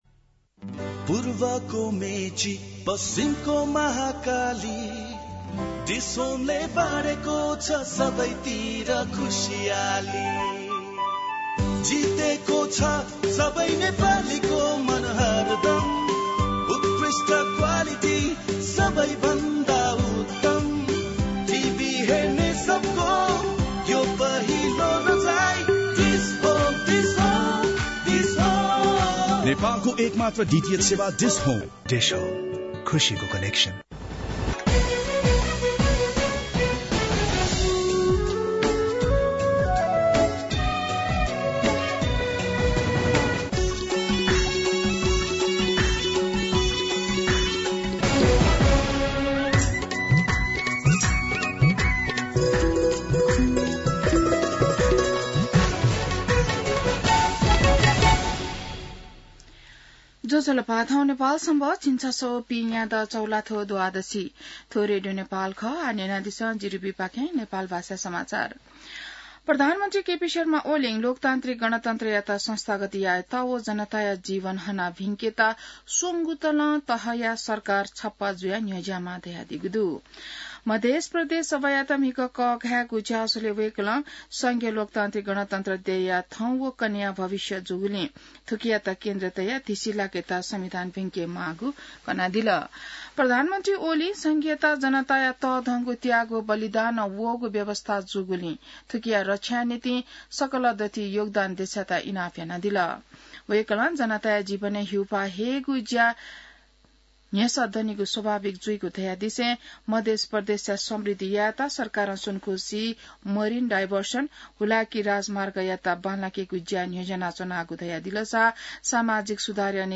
नेपाल भाषामा समाचार : २७ चैत , २०८१